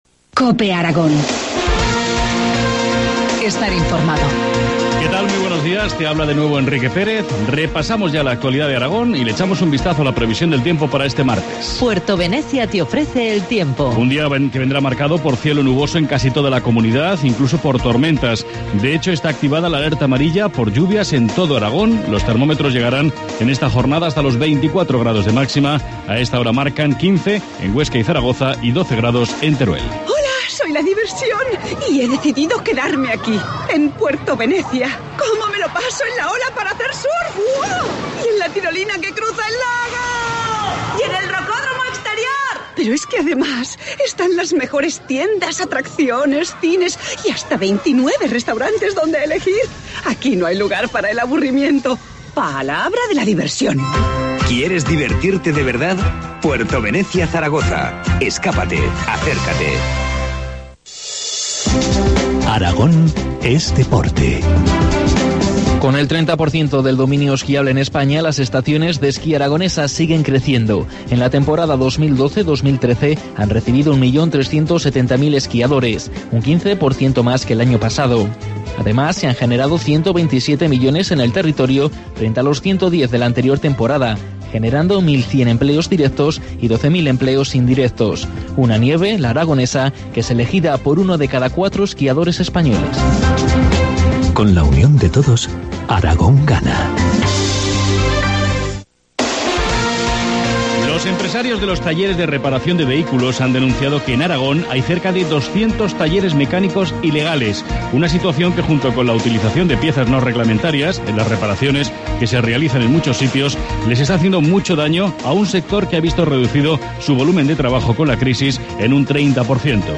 Informativo matinal, martes 14 de mayo, 8.25 horas